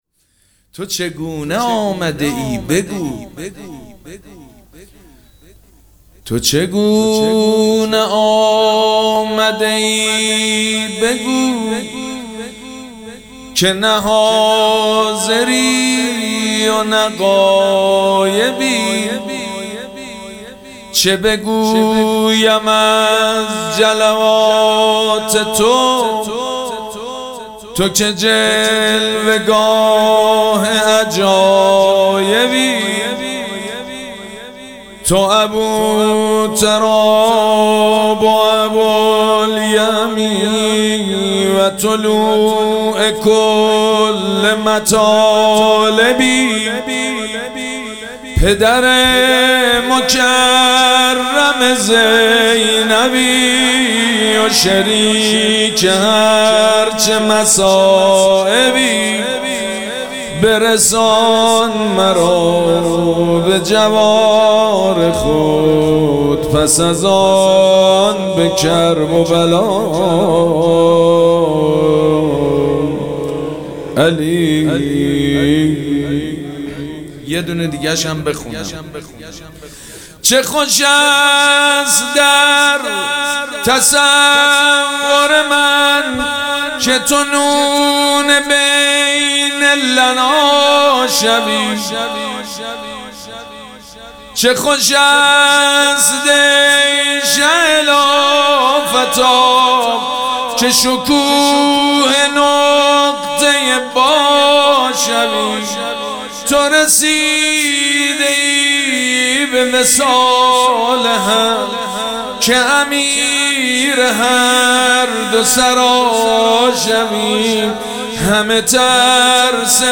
مراسم جشن ولادت حضرت زینب سلام‌الله‌علیها
شعر خوانی
مداح